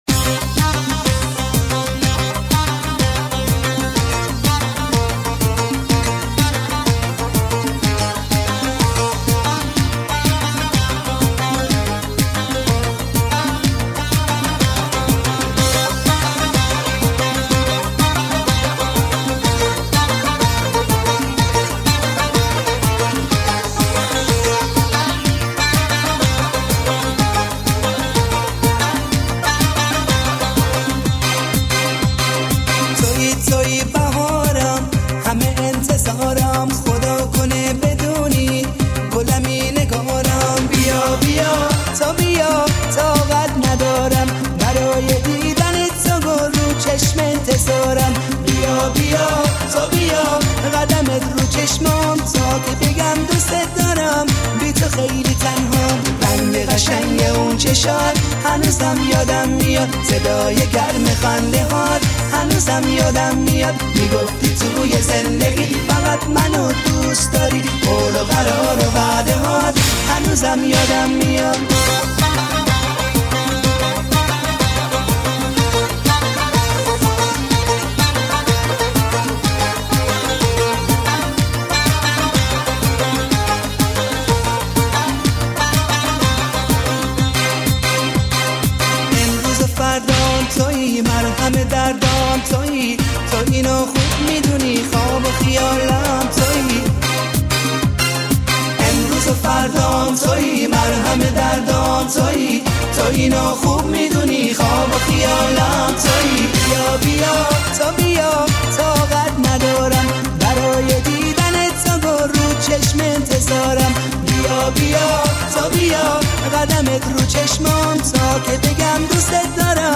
ژانر: پاپ
توضیحات: ریمیکس شاد ترانه های قدیمی و خاطره انگیز